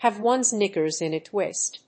アクセントhàve [gèt] one's kníckers in a twíst